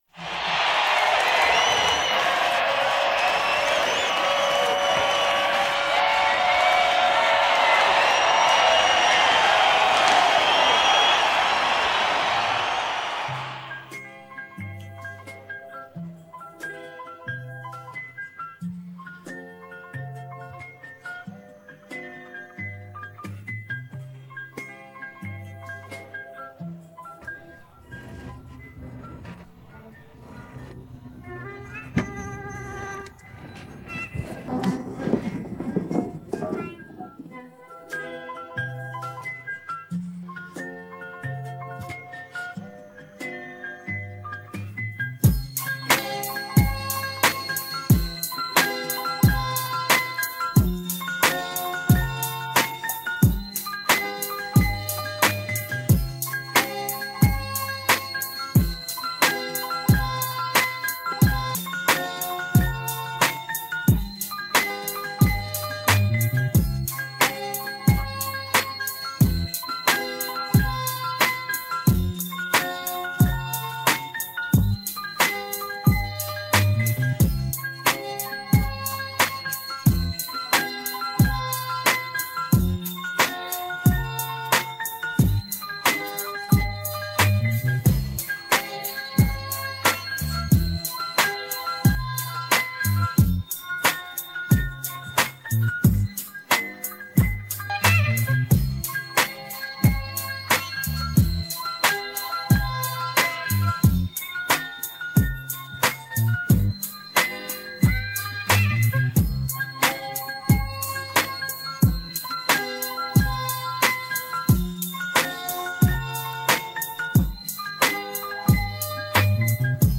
Pop R&B